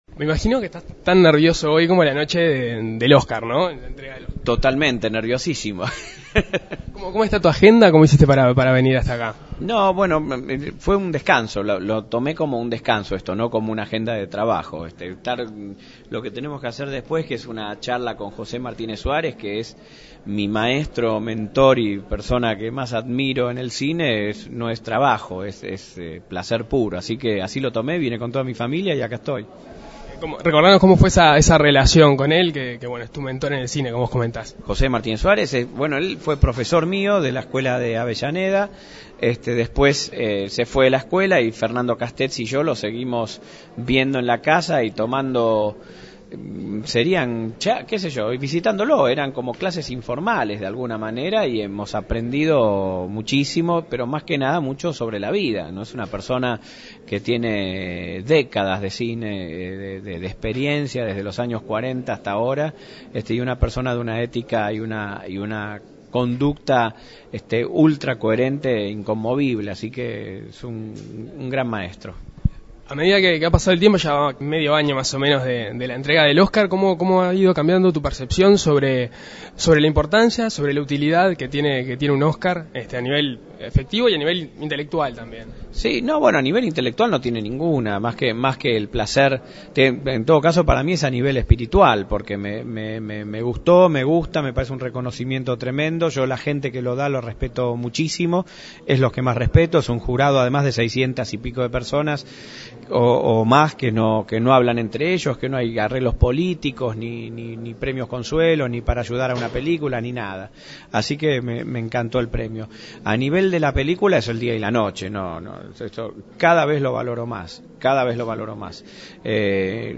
A seis meses de recibir el Oscar a la mejor película en lengua extranjera por "El secreto de sus ojos", fue distinguido como la personalidad del año para el cine de la región. El cineasta dialogó en la Segunda Mañana de En Perspectiva.